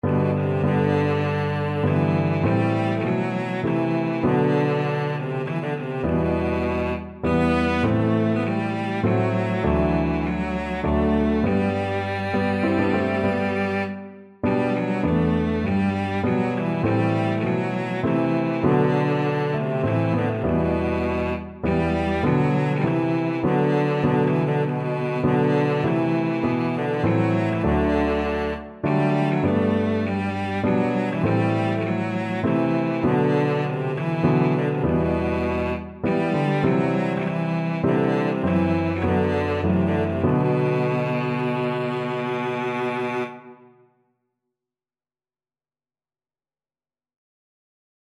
Cello
Traditional Music of unknown author.
G major (Sounding Pitch) (View more G major Music for Cello )
Con moto
3/4 (View more 3/4 Music)
Israeli